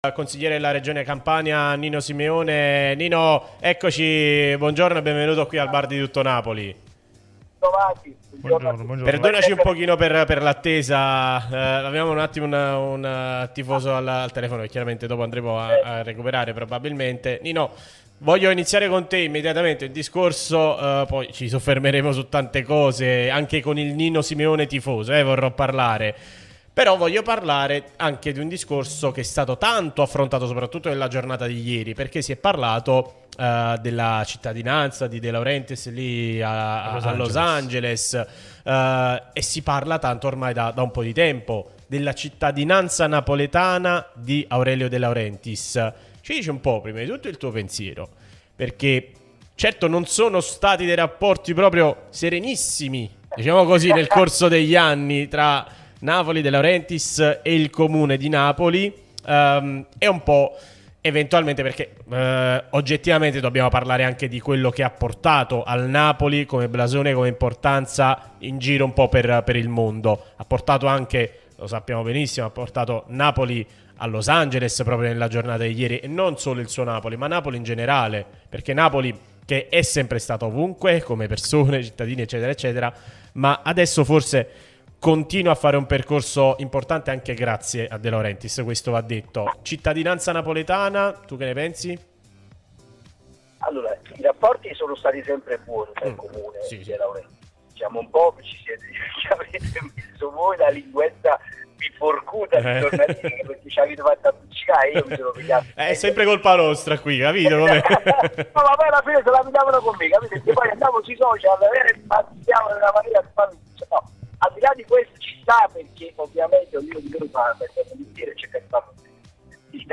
Nino Simeone, consigliere comunale e presidente della commissione trasporti, infrastrutture e lavori pubblici, è intervenuto su Radio Tutto Napoli